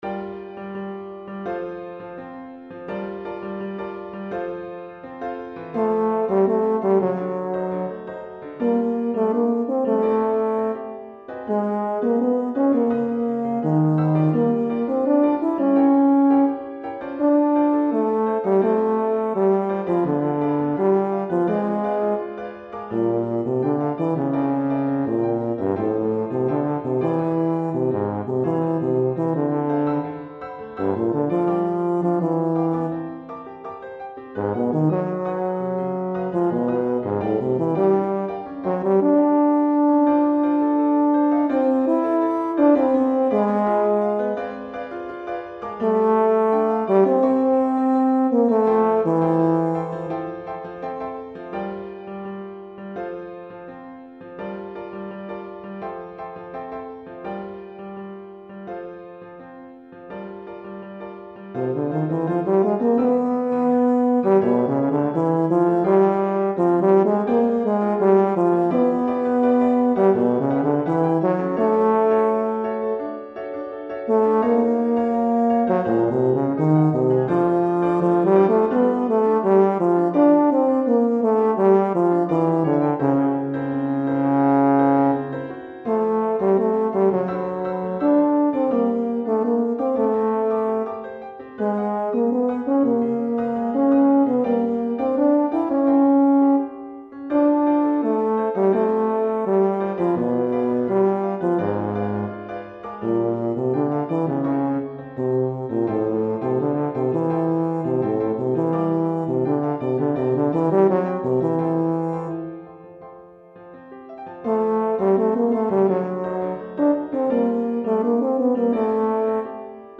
Euphonium et Piano